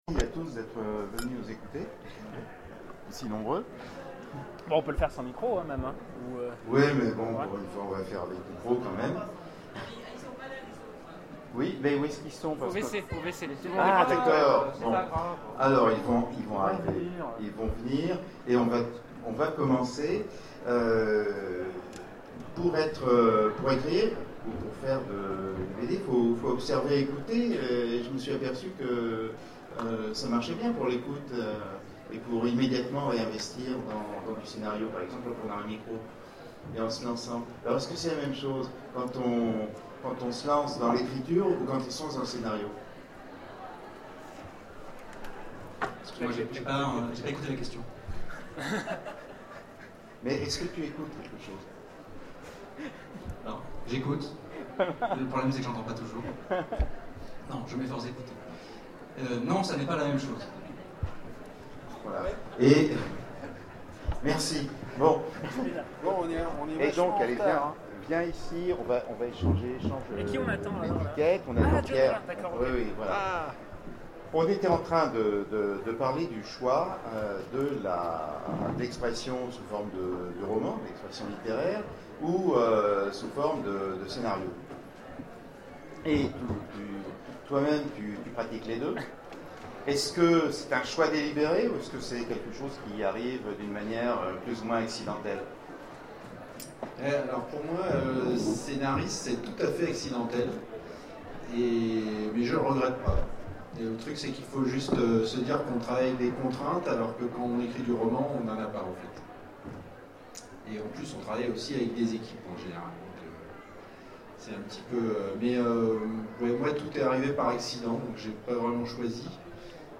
Zone Franche 2014 : Conférence Roman et bande dessinée, les auteurs-scénaristes